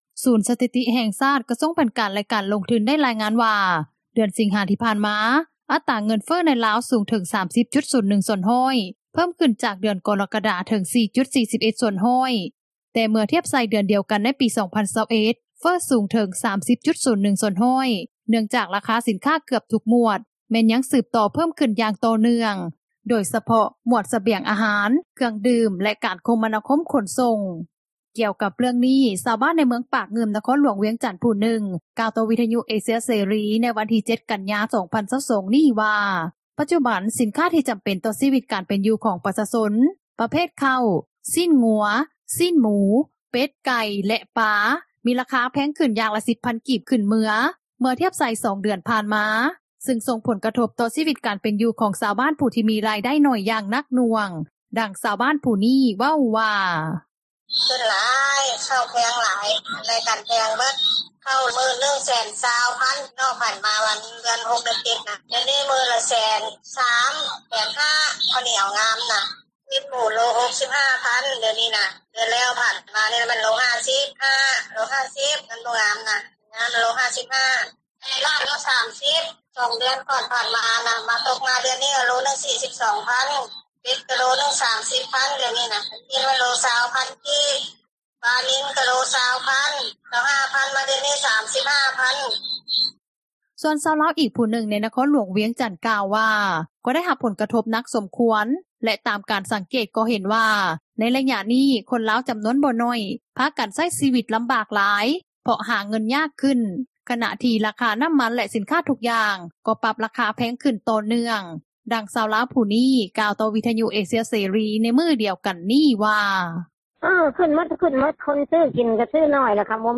ດັ່ງຊາວບ້ານຜູ້ນີ້ ເວົ້າວ່າ:
ດັ່ງແມ່ຄ້າຂາຍຊີ້ນໝູ ຜູ້ນີ້ ກ່າວຕໍ່ວິທຍຸເອເຊັຽເສຣີ ໃນມື້ດຽວກັນນີ້ວ່າ:
ດັ່ງແມ່ຄ້າຂາຍເຄື່ອງຍ່ອຍ ແລະອາຫານສົດ ໃນນະຄອນຫຼວງວຽງຈັນ ກ່າວຕໍ່ວິທຍຸເອເຊັຽເສຣີ ໃນມື້ດຽວກັນນີ້ວ່າ: